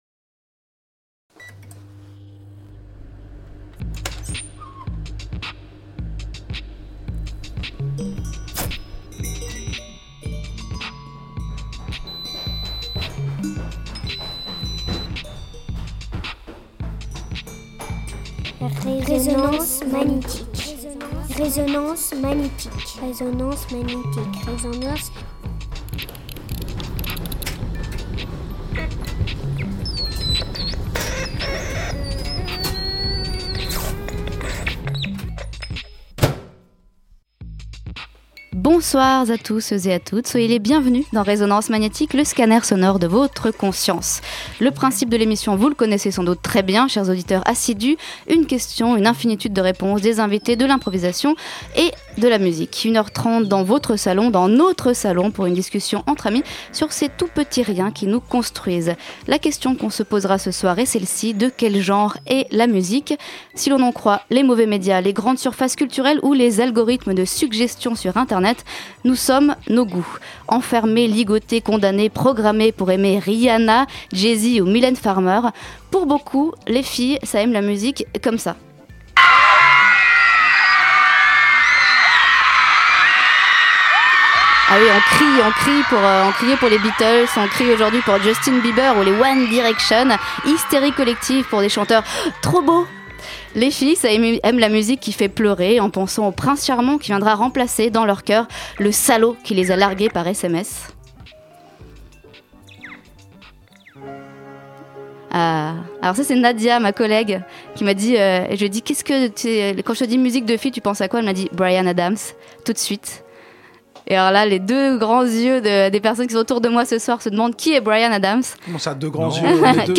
Comment éduque-t-on et s'éduque-t-on à la musique? On vous ouvre les portes de notre salon pour en discuter et écouter quelques disques.